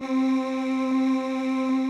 Pad - Angel.wav